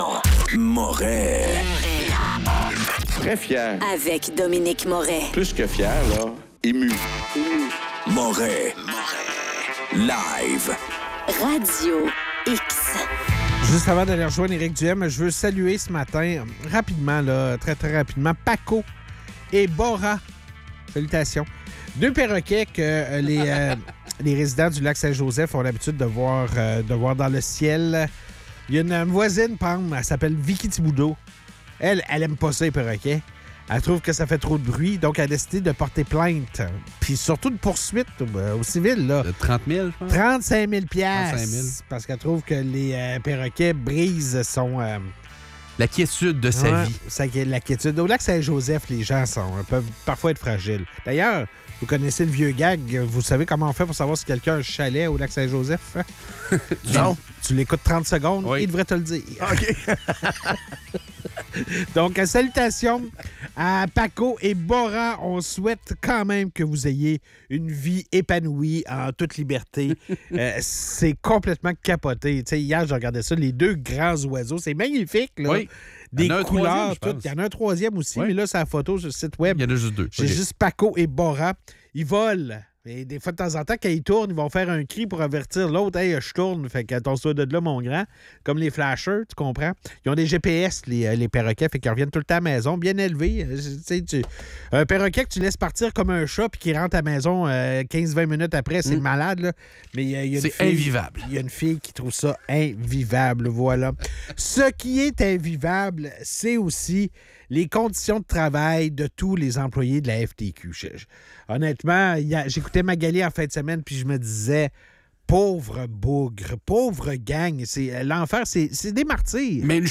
Entrevue avec Éric Duhaime